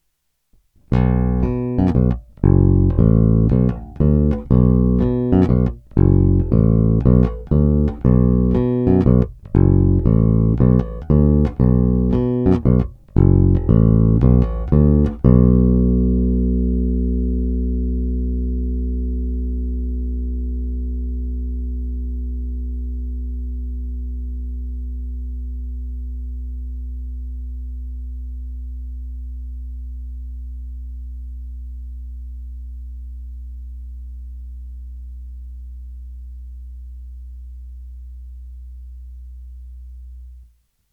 Masívní basy, vrčivé středy, kousavé výšky.
Není-li uvedeno jinak, následující nahrávky jsou nahrány rovnou do zvukovky a dále jen normalizovány.
Hra mezi snímačem a kobylkou